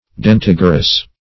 Search Result for " dentigerous" : The Collaborative International Dictionary of English v.0.48: Dentigerous \Den*tig"er*ous\, a. [L. dens, dentis, tooth + -gerous.] Bearing teeth or toothlike structures.